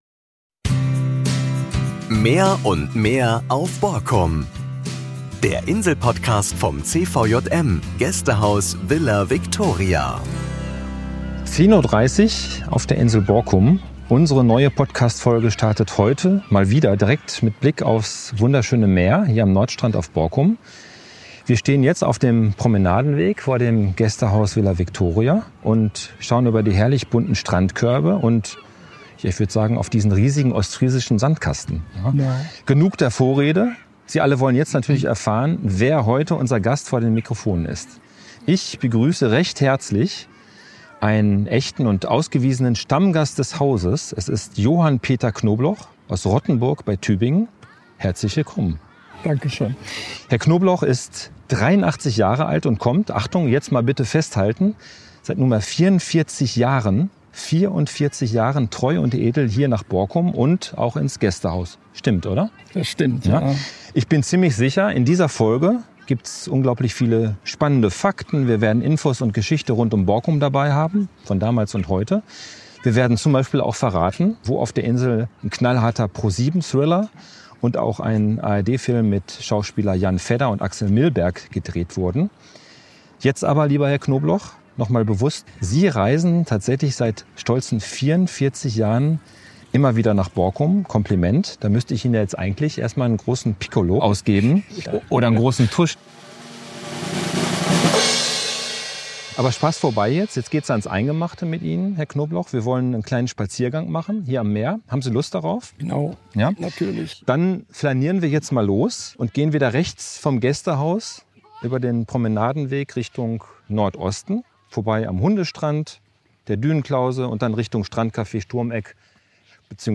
In dieser Borkum Podcast-Folge nehme ich Sie mit auf einen Insel-Spaziergang mit Interview entlang des wunderschönen Meeres, entlang des Nordstrands, vorbei am Café Sturmeck und Café Seeblick, bis in die Dünenlandschaft direkt an der Meereskante.